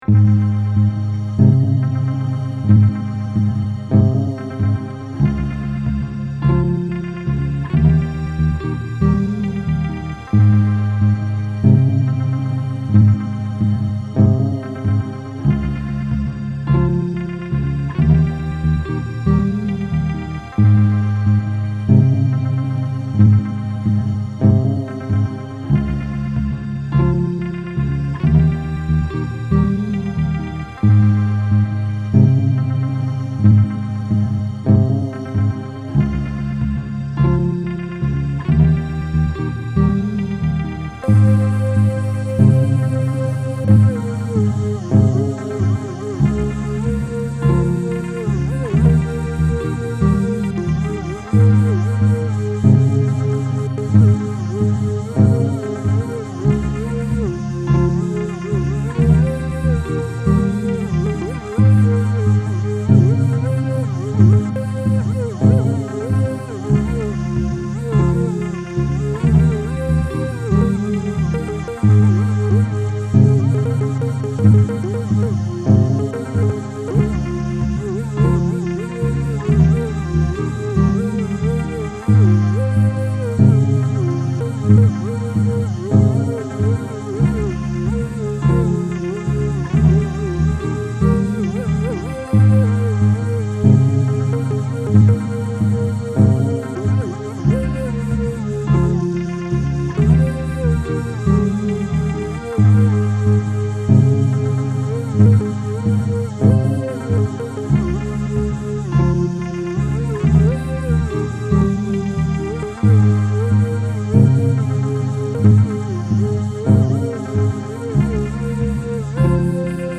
Filed under: Instrumental | Comments (2)